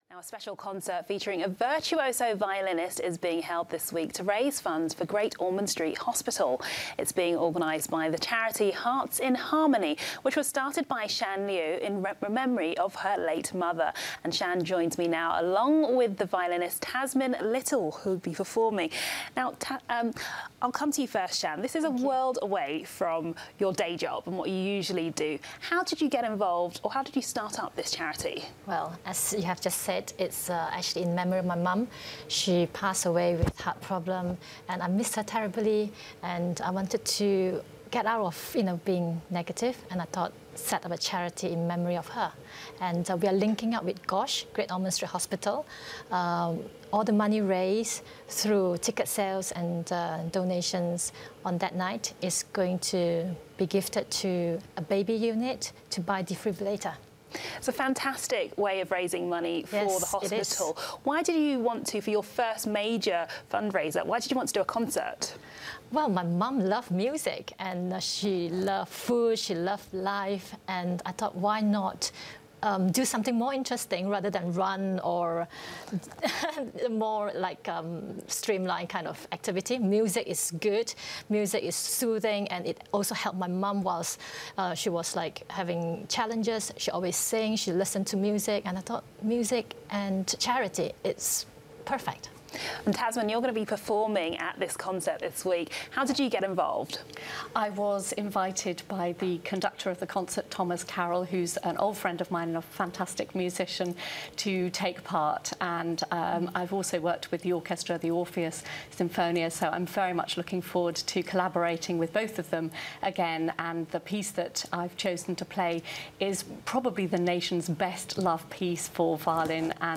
LISTEN TO TASMIN TALKING on LONDON LIVE ABOUT THIS PERFORMANCE yesterday.